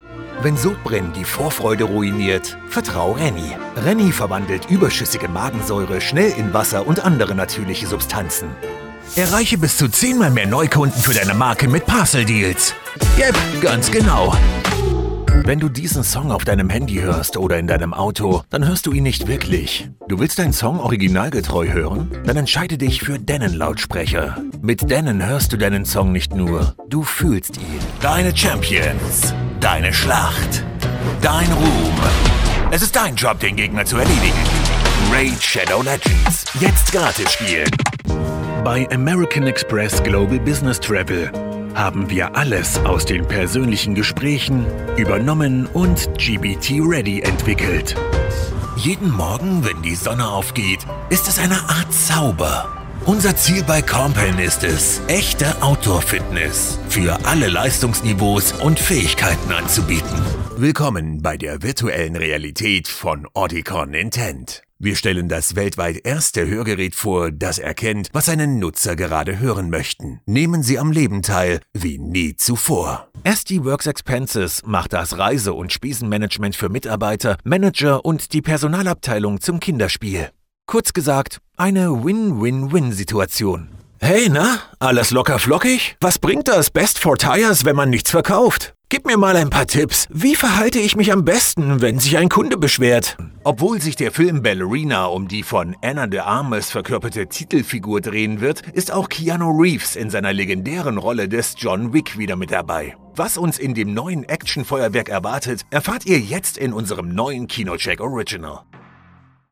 Naturelle, Mature, Chaude, Cool, Profonde
Toutes les voix-off sont enregistrées dans un studio équipé de manière professionnelle.